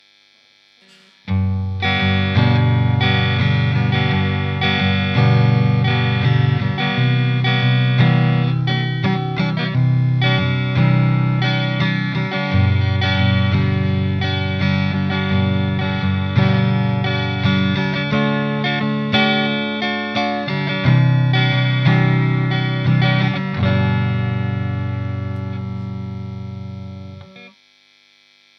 guitare (strat tokai ou melody maker ou westone thunder) -> ampli -> cab 2x12 greenback -> micro shure PG57 -> preamp micro -> numérisation (M audio 1010lt)
un petit bémol pour la prise de son sur le coté "pétillant" des samples en satu, en direct c'est pas "pétillant" de l'aigu.
strat en clean (micro neck)
clean.mp3